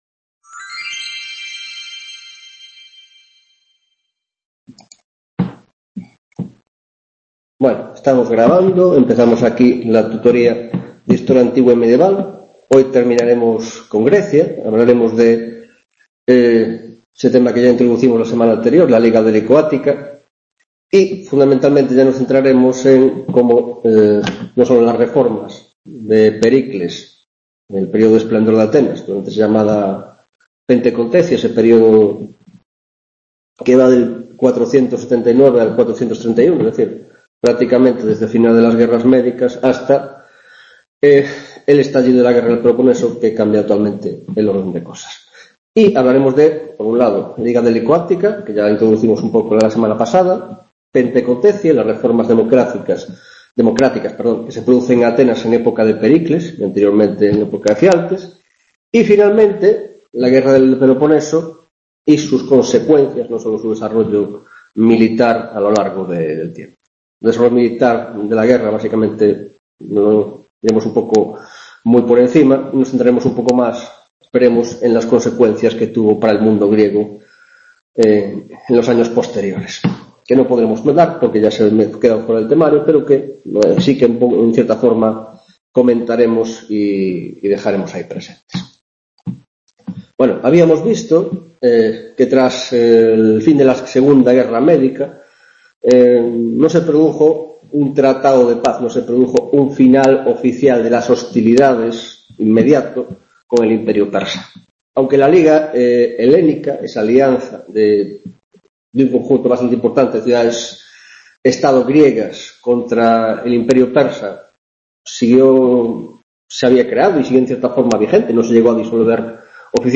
4ª Tutoria de Historia Antigua y Medieval, grado en Filosofia